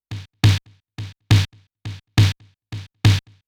BOOM SD   -L.wav